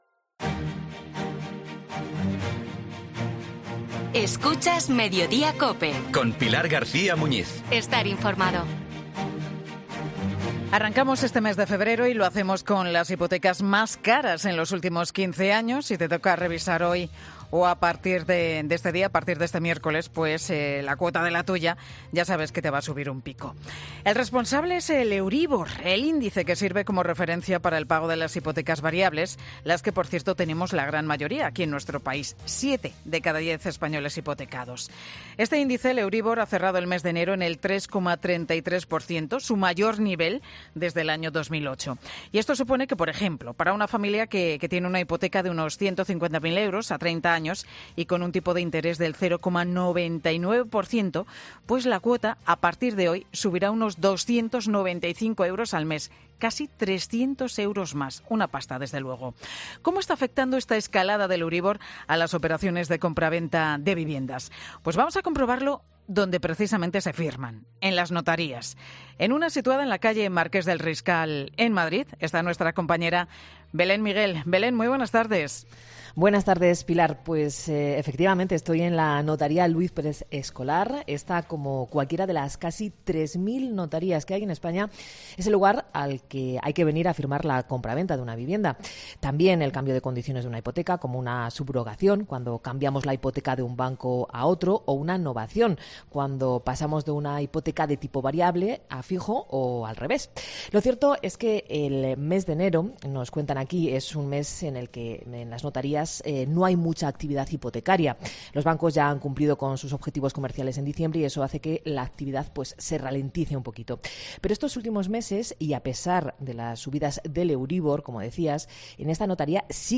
Un notario ante la caída de firmas de hipotecas por el euribor: "En enero se han firmado solo tres"